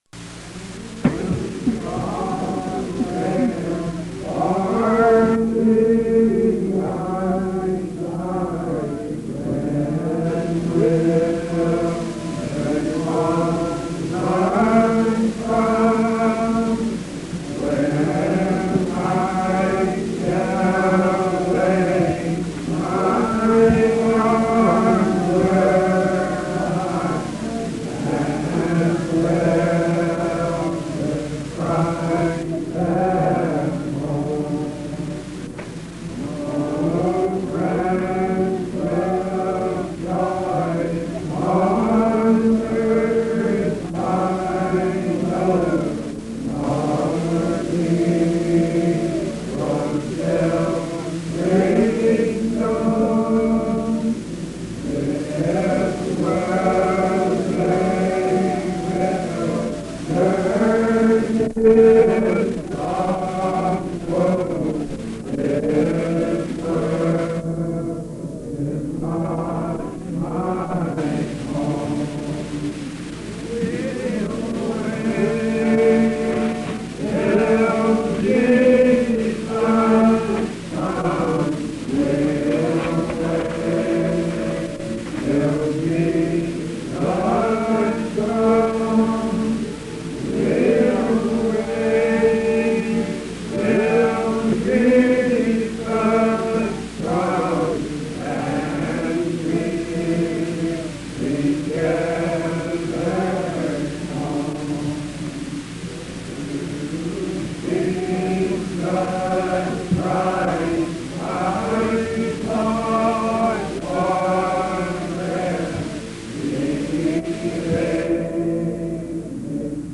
Low Quality Singing Clip
A short, low quality recording of singing